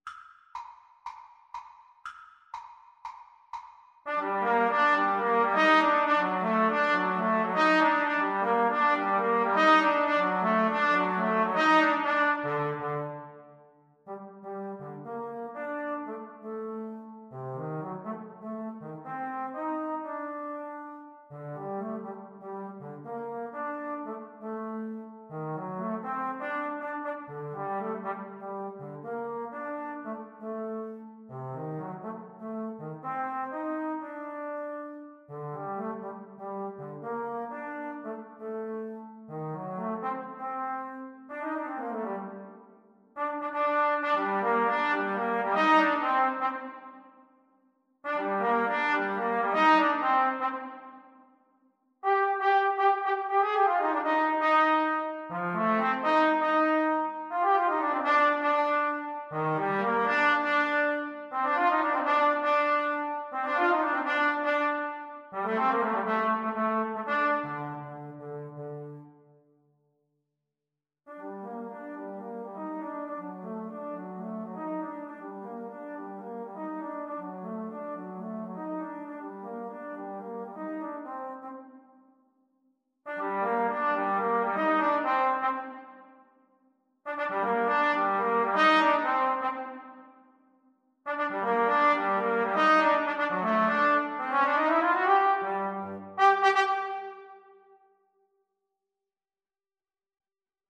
A firey salsa-inspired piece.
Energico =120
4/4 (View more 4/4 Music)
Jazz (View more Jazz Trombone Duet Music)